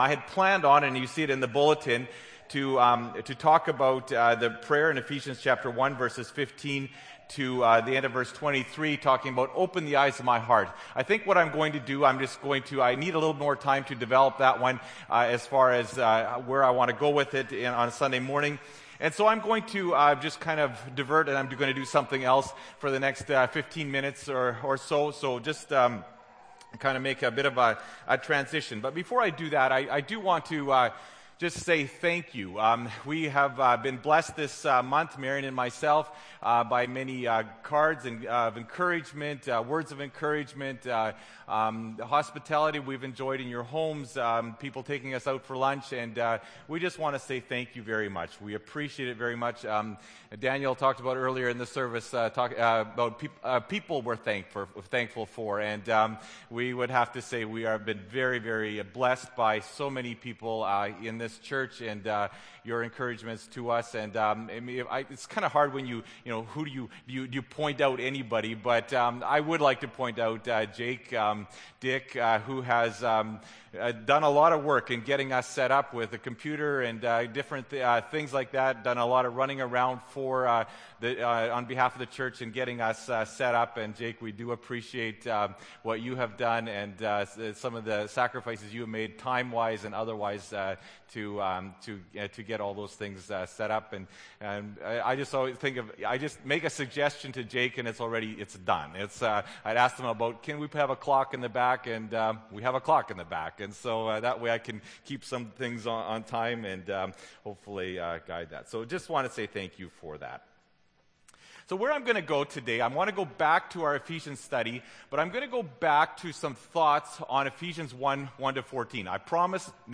Oct. 28, 2012 – Sermon